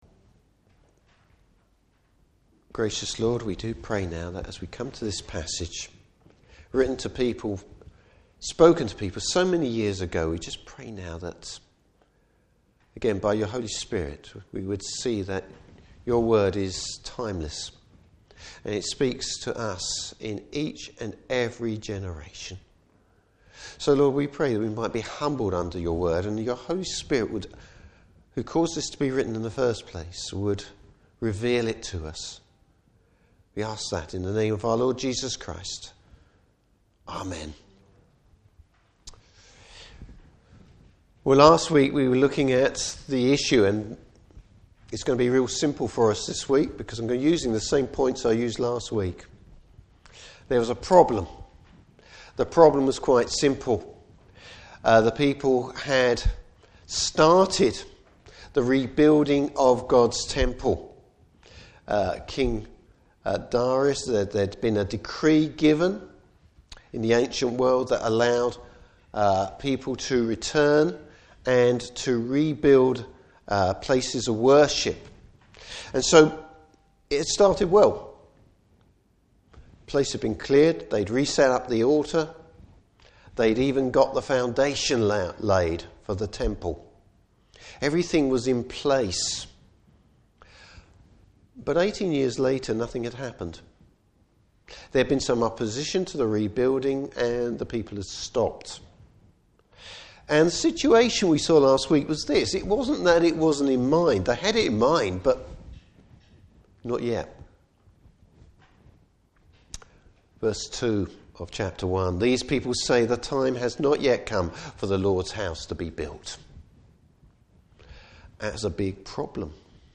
Service Type: Morning Service What are we to do when we encounter difficulties in Christian living?